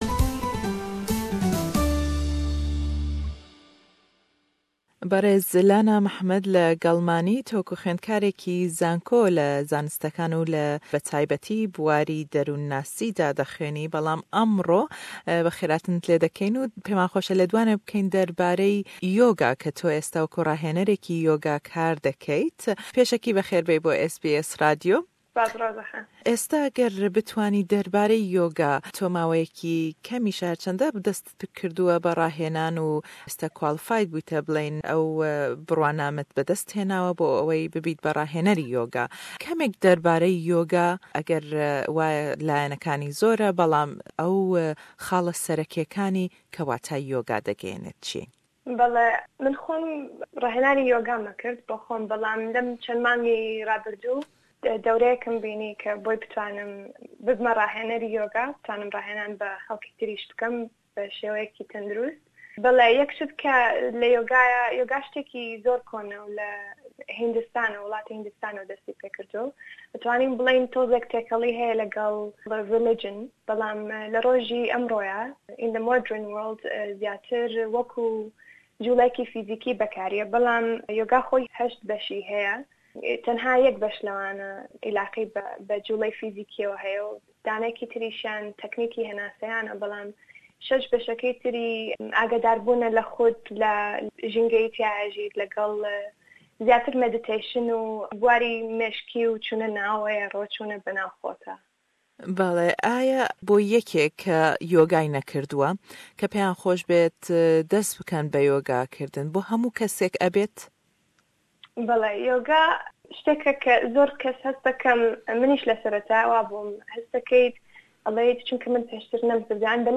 herweha rahênrî Yoga ye. le em lêdwane derbarey sûdekanî yoga pirsiyarî lê dekeyn.